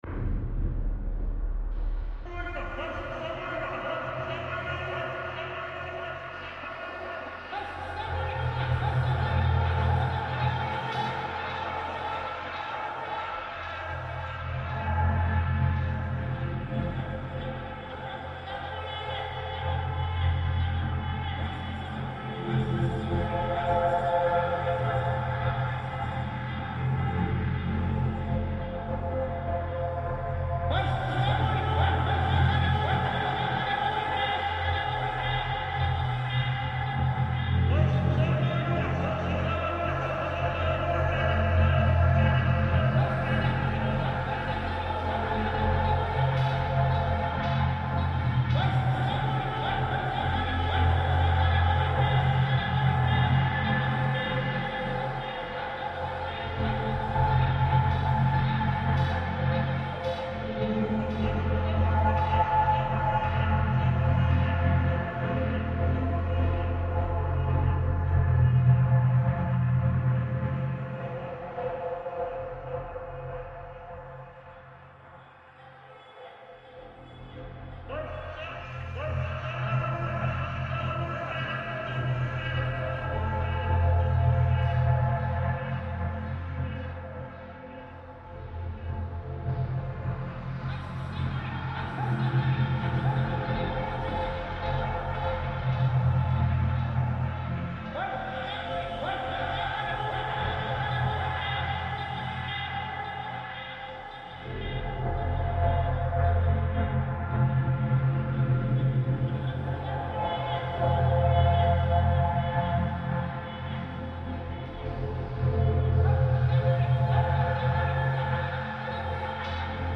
Tunisian street sellers reimagined